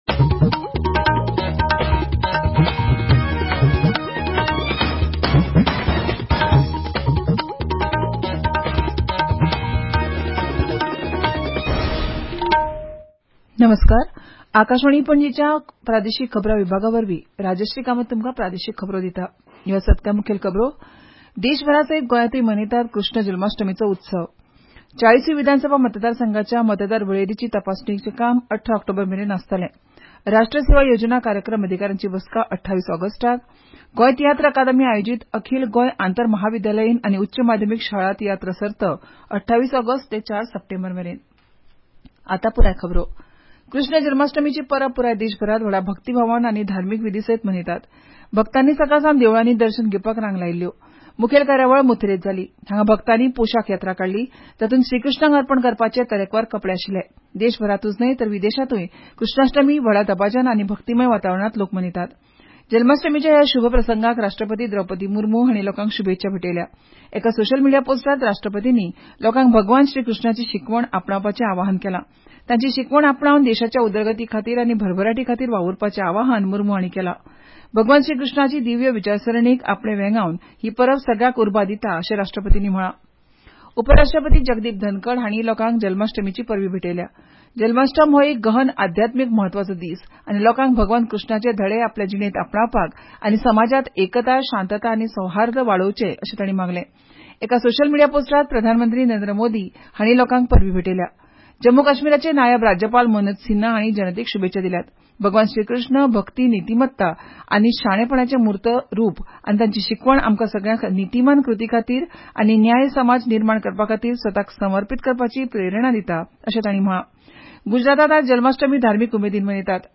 Transcript summary Play Audio Midday News